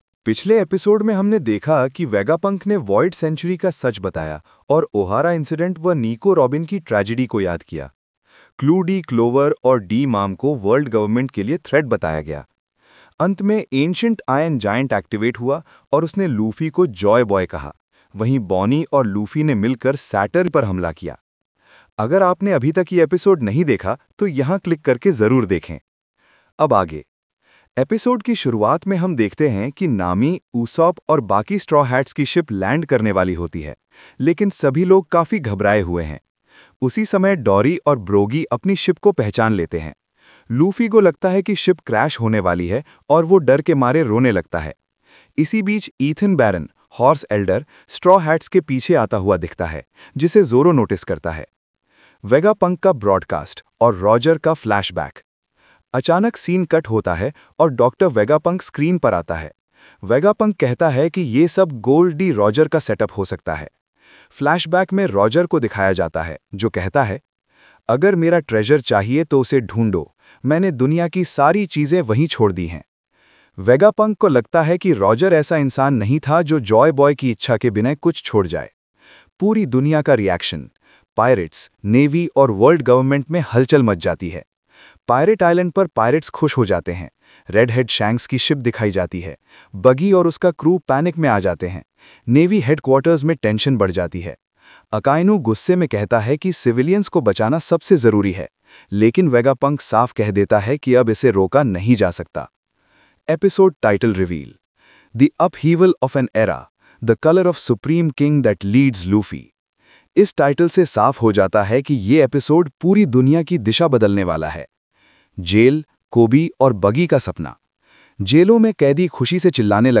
इस तरह आपको episode की story बिल्कुल anime narration जैसी feel देगी।